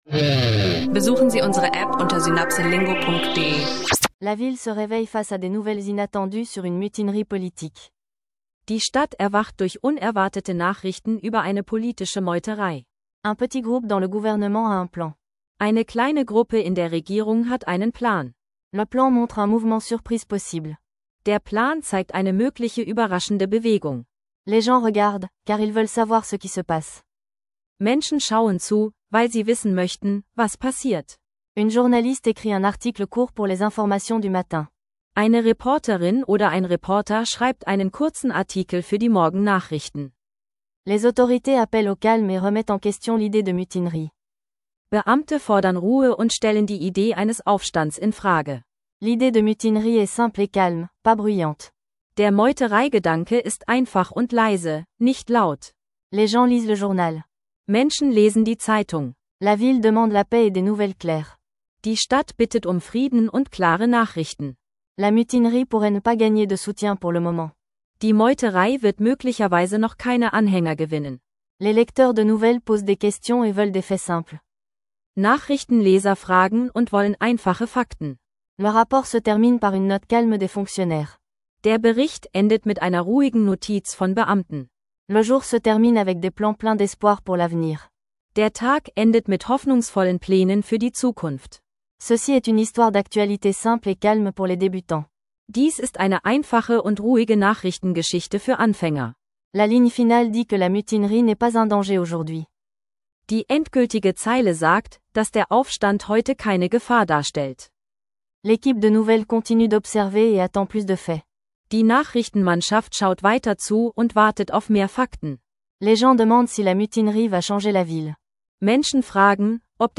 Lerne mit einer einfachen, ruhigen Geschichte Französisch – Mutinerie-Vokabeln, Bankwesen und Alltagsbegriffe verständlich erklärt.
Eine klare, einfache Französisch-Lektion für Anfänger: